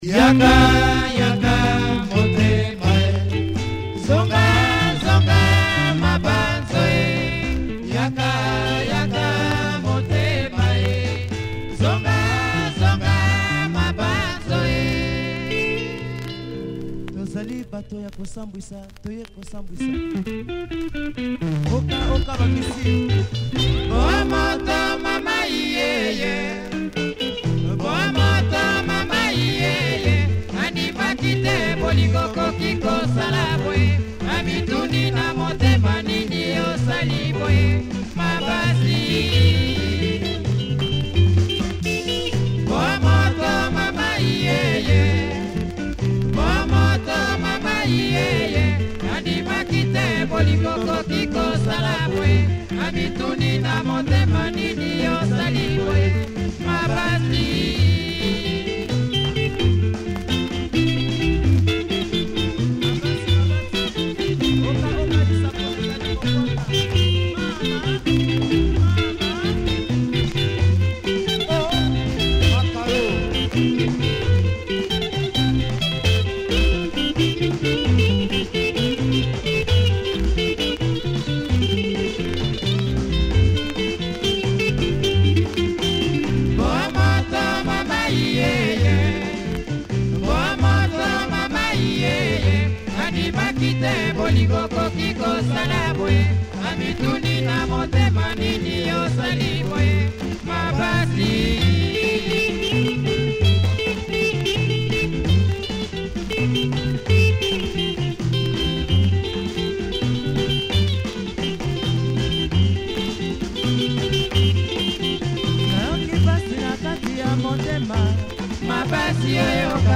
Nice Lingala track